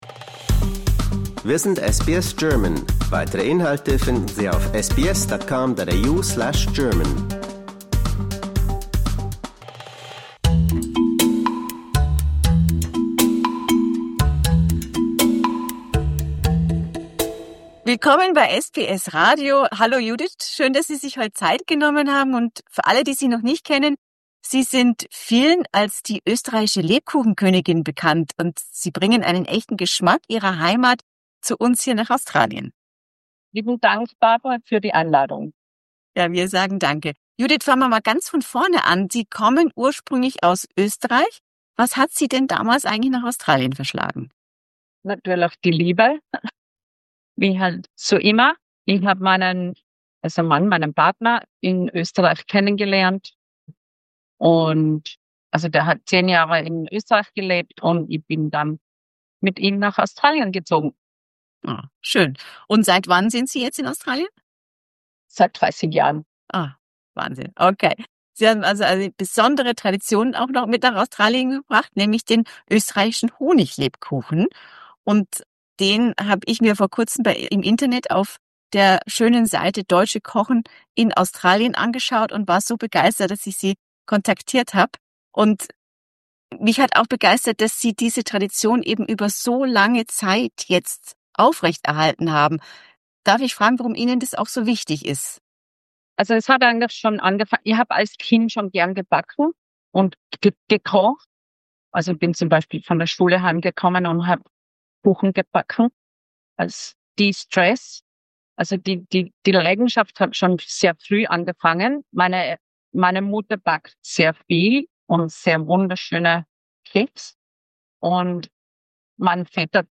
Discover more stories, interviews, and news from SBS German in our podcast collection .